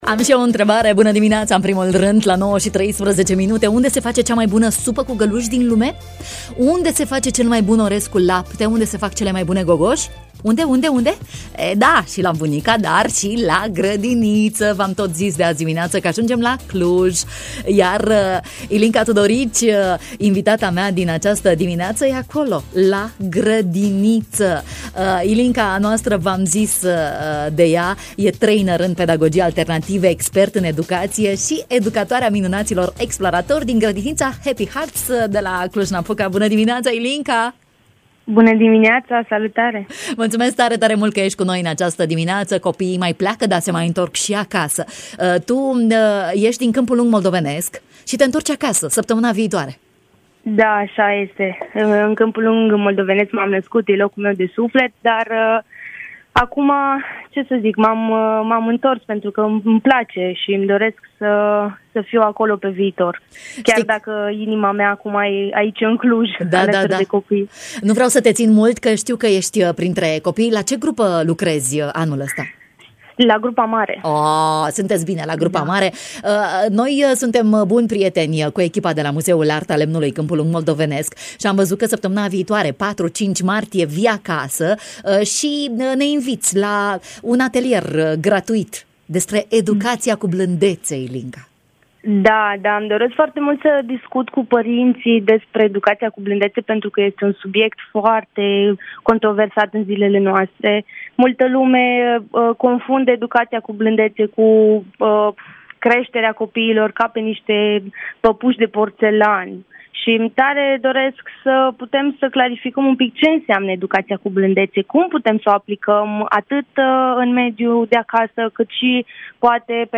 (Radio Iași)